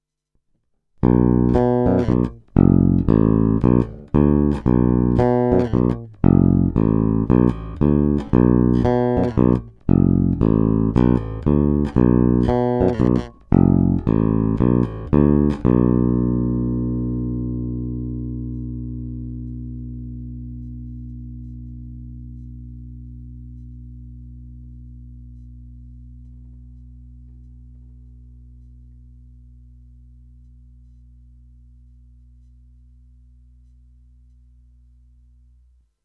Pevný, kovově vrnící, poměrně agresívní, prosadí se.
Není-li uvedeno jinak, následující nahrávky jsou provedeny rovnou do zvukové karty, s plně otevřenou tónovou clonou a bez zařazení aktivní elektroniky.
Snímač u kobylky